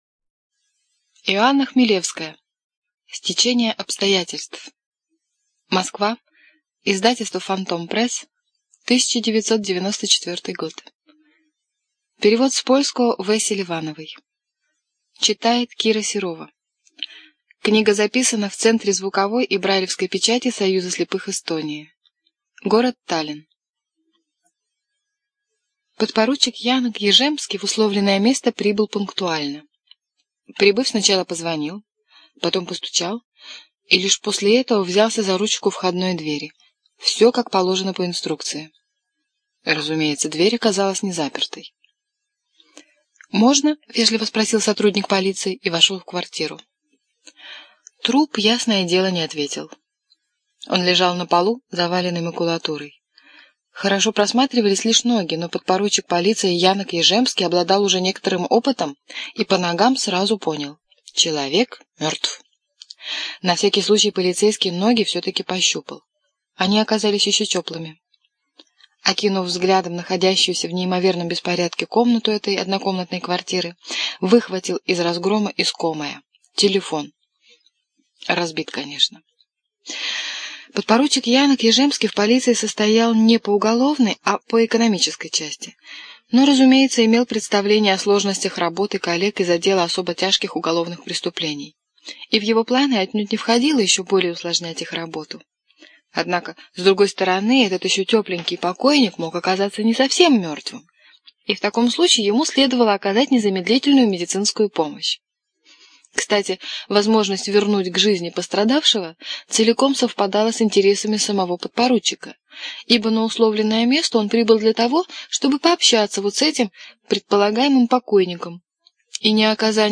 ЖанрДетективы и триллеры